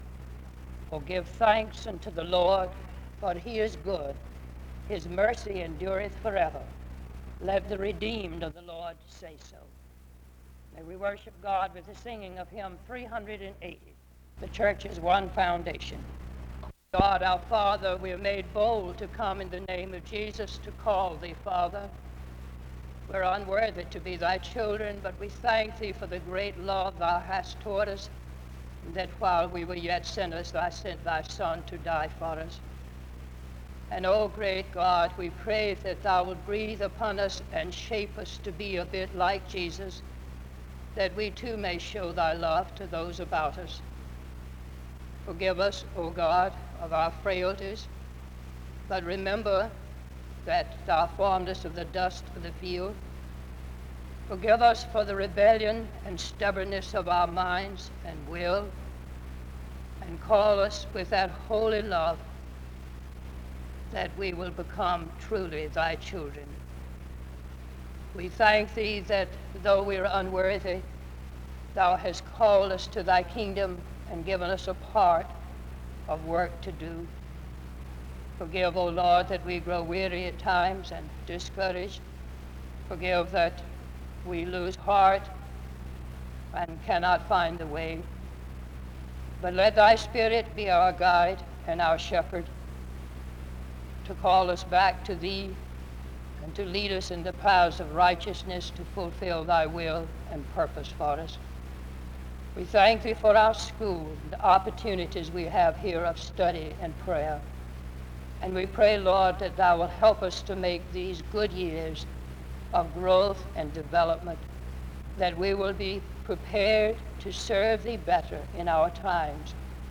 SEBTS Chapel
The service begins with a prayer (0:00-2:23).
He closes in prayer (22:01-22:54).